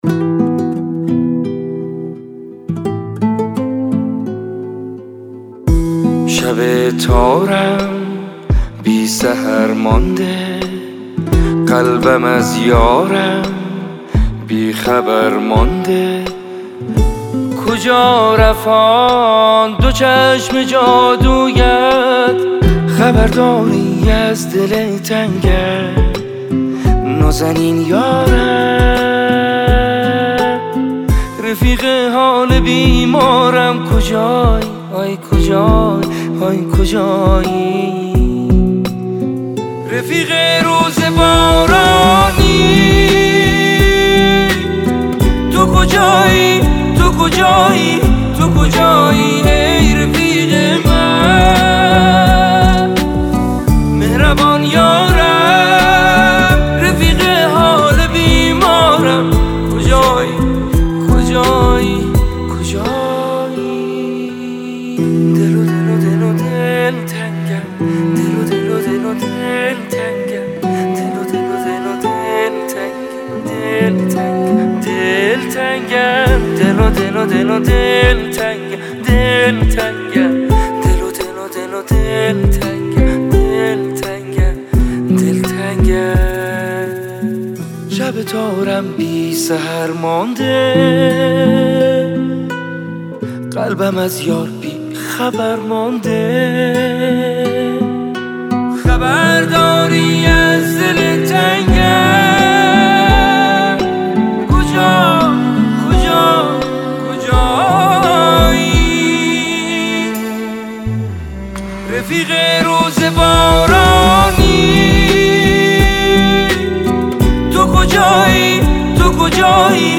عاشقانه